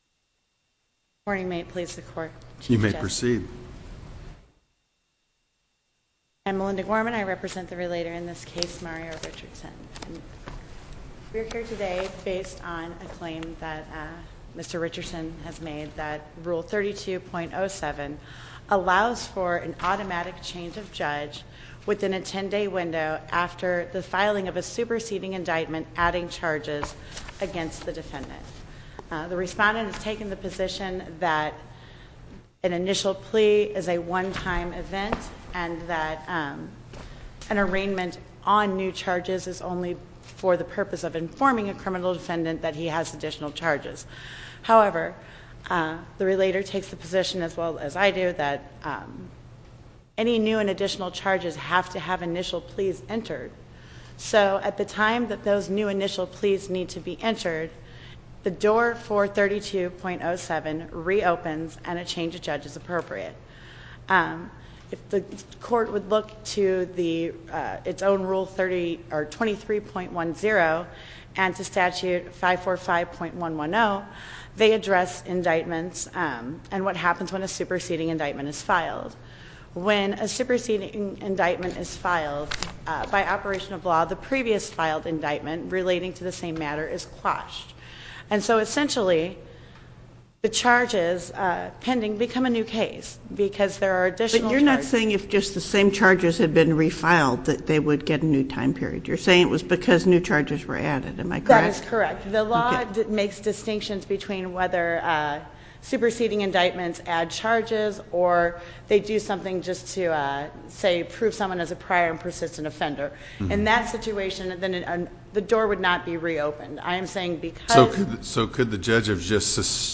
link to MP3 audio file of oral arguments in SC97317